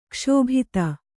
♪ kṣōbhita